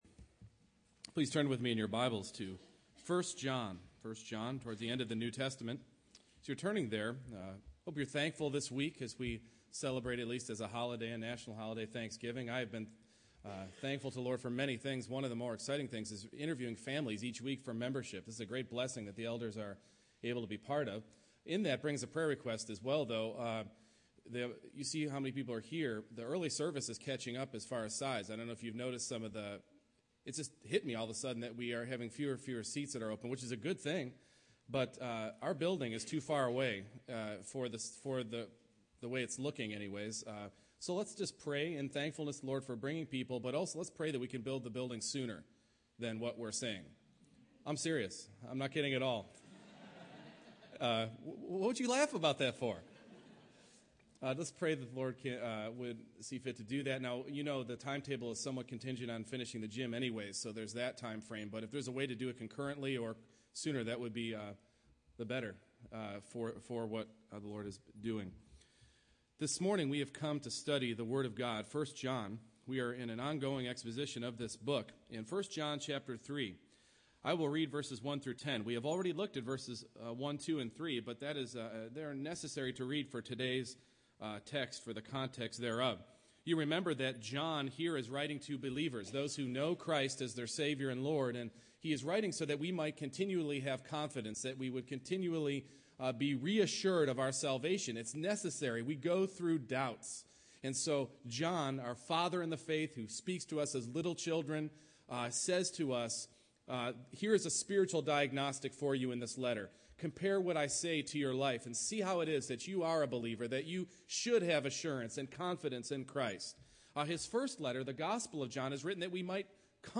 1 John 3:4-10 Service Type: Morning Worship We are not saved by our practice